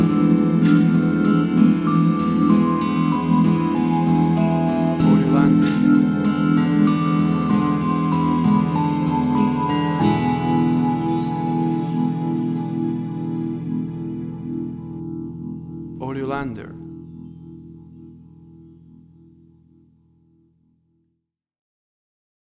WAV Sample Rate: 16-Bit stereo, 44.1 kHz
Tempo (BPM): 96